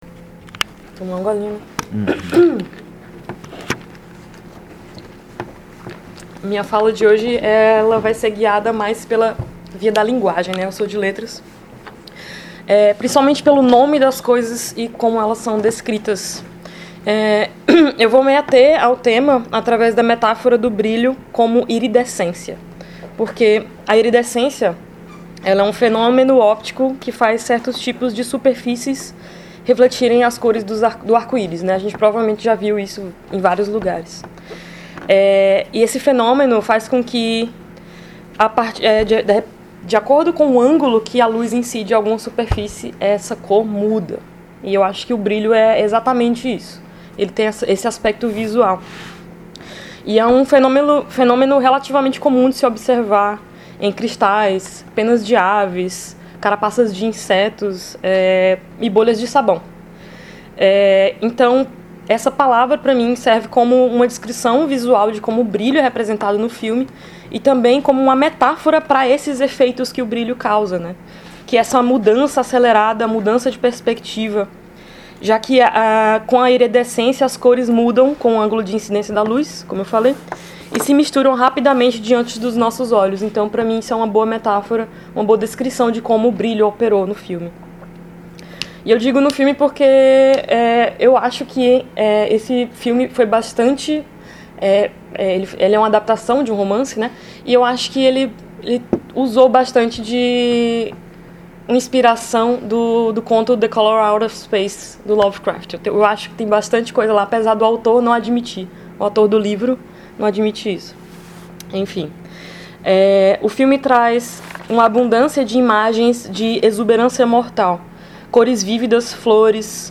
Comentários dos(as) debatedores(as) convidados(as)
na sessão de exibição e debate do filme “Aniquilação” (ano de produção: 2018), do diretor Alex Garland, realizada em 13 de abril de 2023 no Auditório Elke Hering da Biblioteca Central da UFSC.